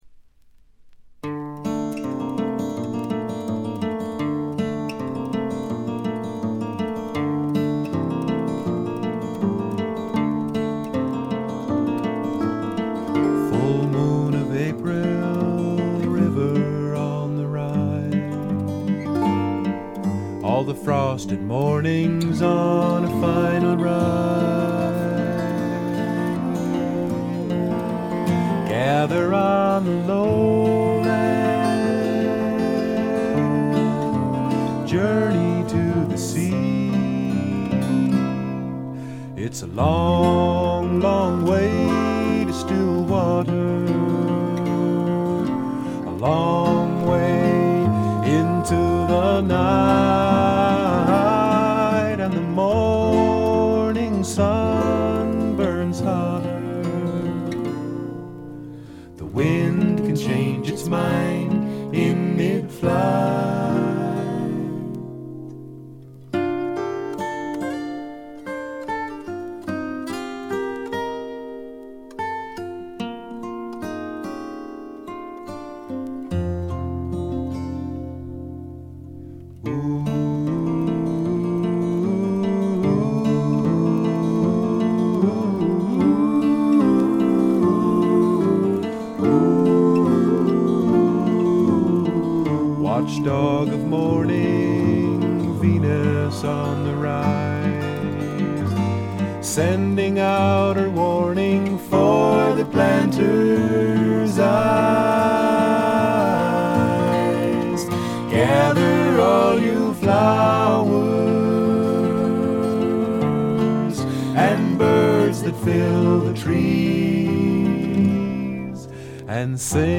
微細なチリプチわずか。
全体に静謐で、ジャケットのようにほの暗いモノクロームな世界。
試聴曲は現品からの取り込み音源です。